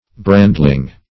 Search Result for " brandling" : The Collaborative International Dictionary of English v.0.48: Brandling \Brand"ling\, Brandlin \Brand"lin\, n. (Zool.)